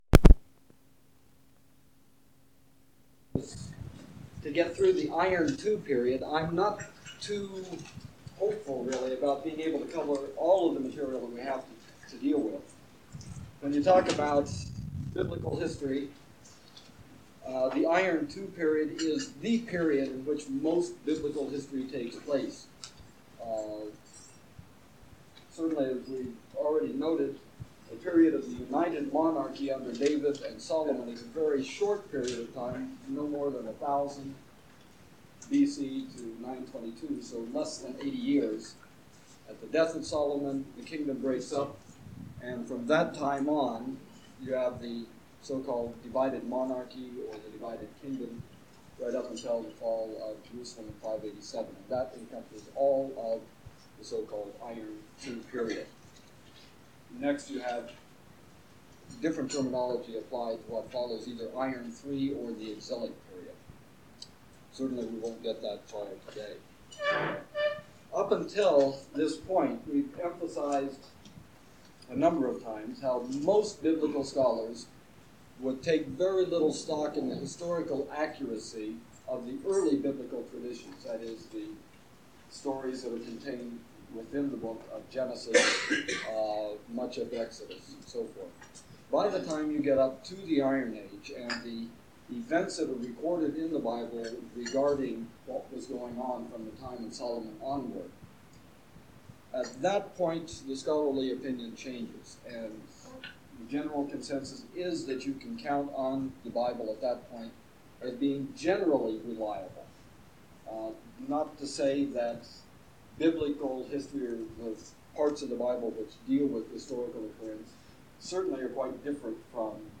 Archaeology of Jordan and Biblical History - Lecture 14